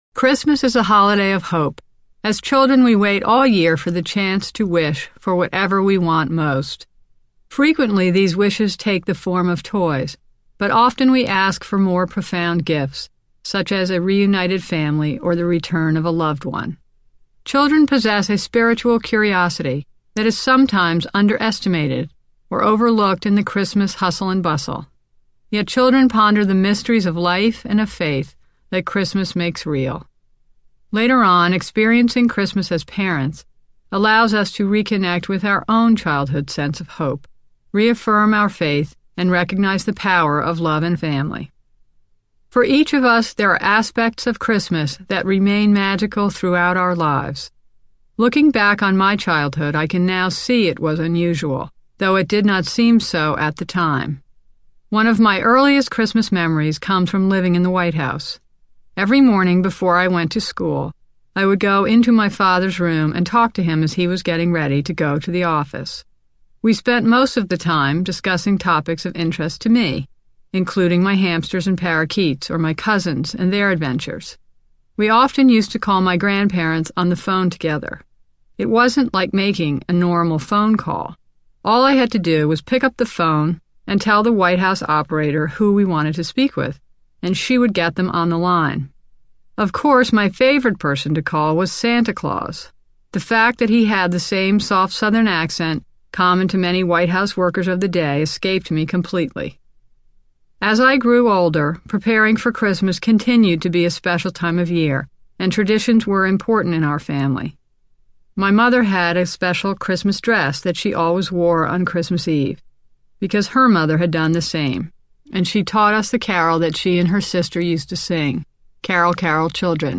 A Family Christmas Audiobook
The introduction, written and read by Caroline, relates the importance of the holiday to her family, and shares how the literature in this collection is an essential part of her own tradition.
Full Cast
Family Christmas HB Sample.mp3